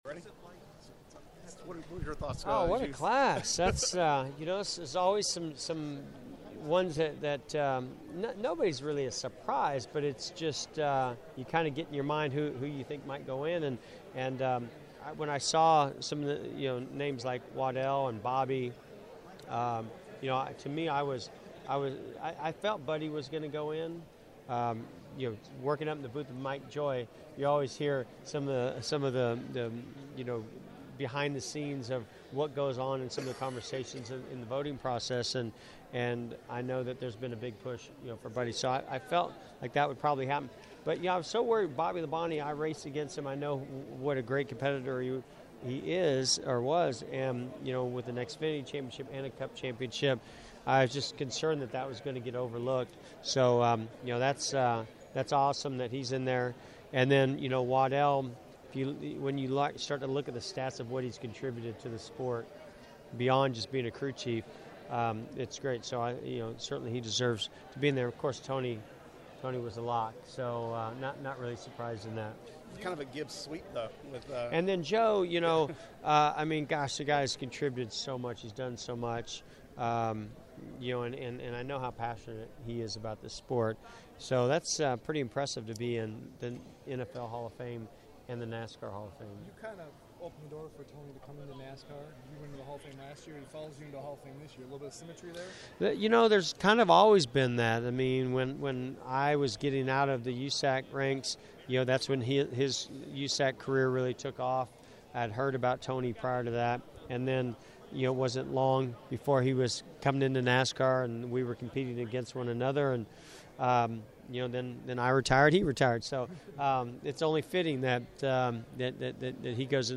Media Interviews: